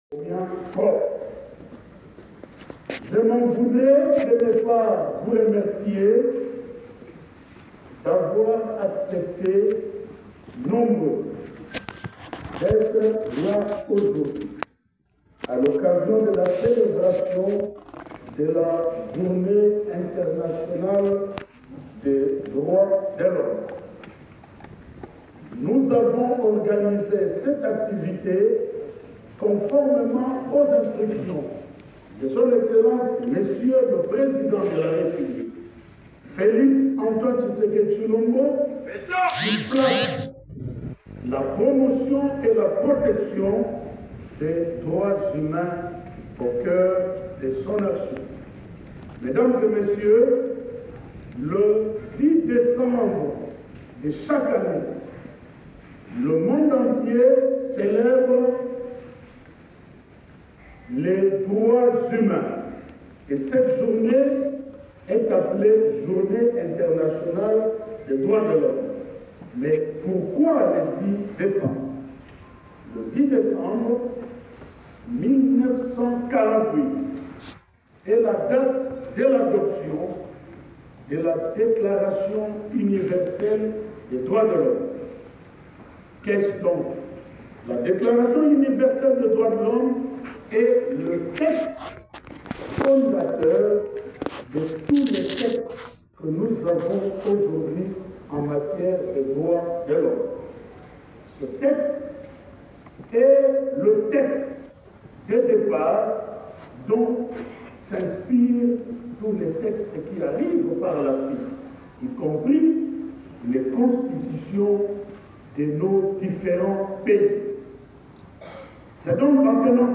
Samuel Mbemba a formulé cette requête lors de la Journée internationale des droits de l’homme, célébrée le 10 décembre de chaque année. À Kinshasa, une manifestation a été organisée au Palais du peuple en présence de plusieurs catégories socio-professionnelles, dont la ministre du Genre, Famille et Enfant.